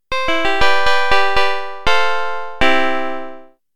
I have a simple virtual analog synthesizer going that can be used to synthesize piano-like sounds.
Sample 2 (C major)
sample-cmajor2.mp3